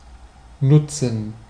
Ääntäminen
IPA: /ɛks.plwa.te/